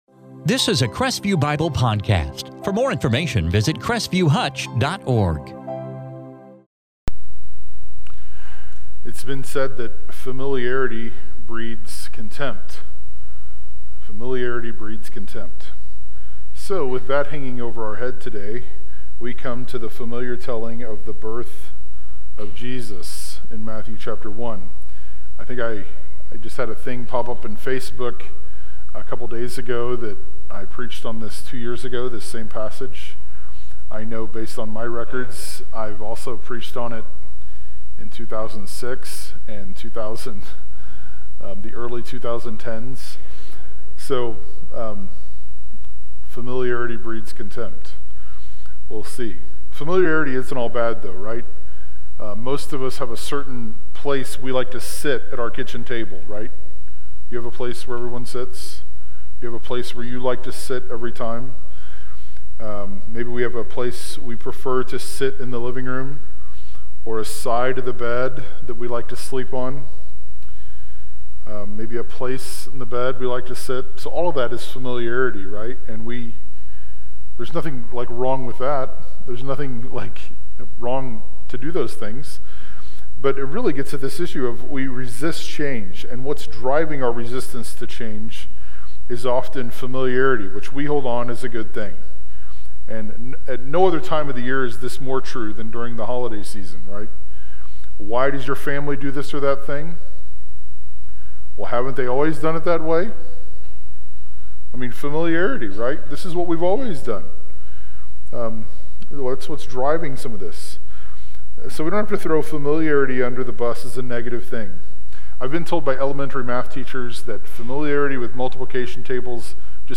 In this sermon from Matthew 1:18-25